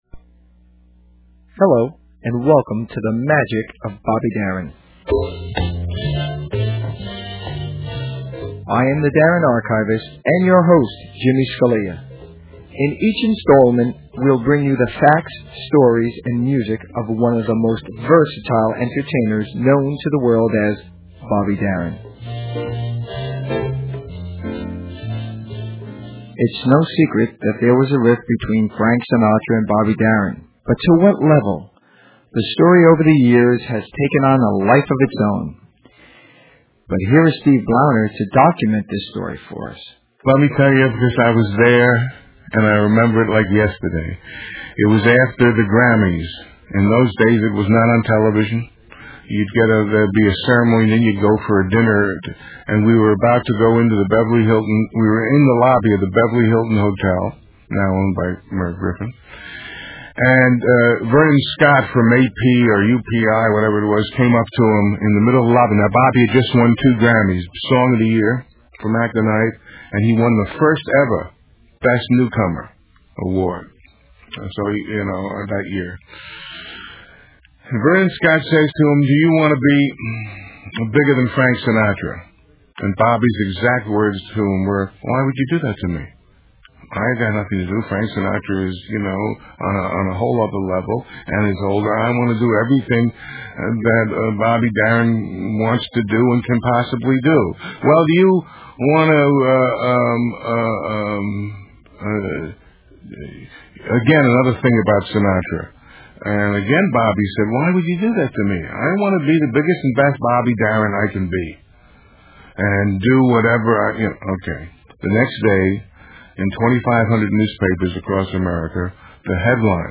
Technical Note: Please keep in mind that due to time and space contraint on the internet, and legal worries of sharing too much, the music portions have been edited and the quality is subpar.